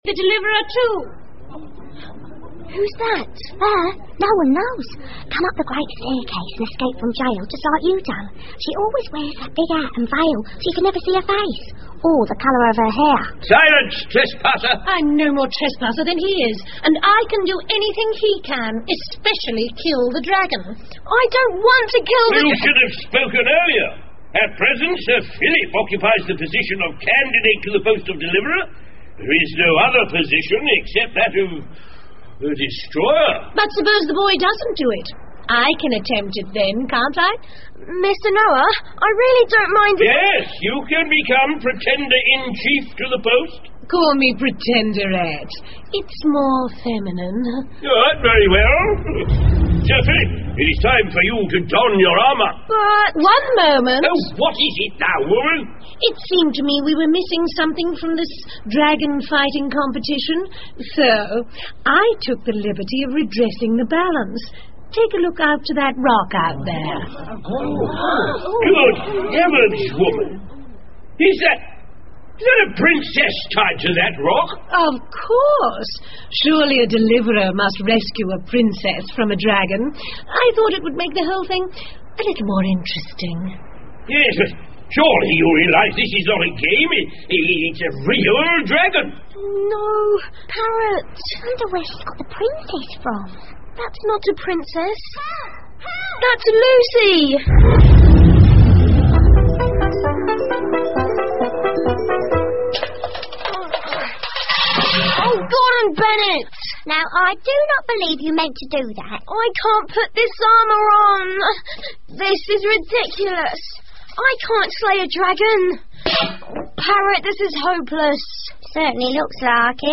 魔法之城 The Magic City by E Nesbit 儿童广播剧 11 听力文件下载—在线英语听力室